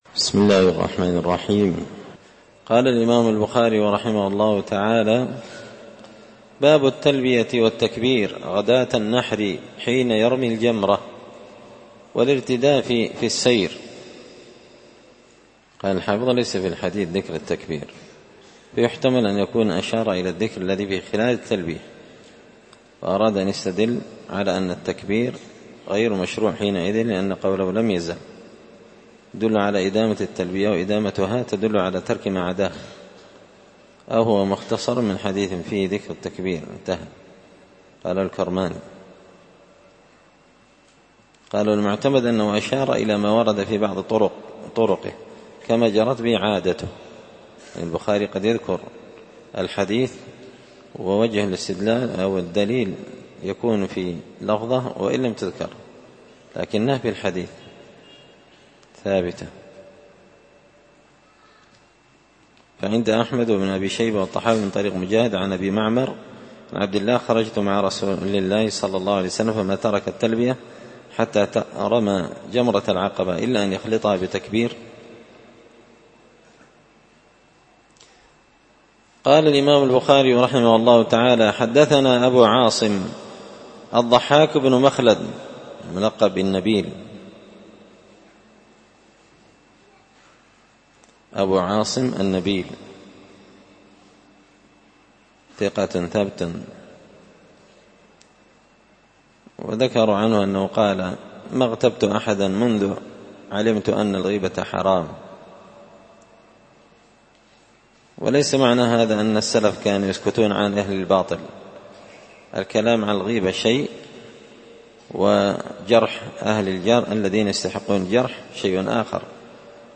كتاب الحج من شرح صحيح البخاري – الدرس 89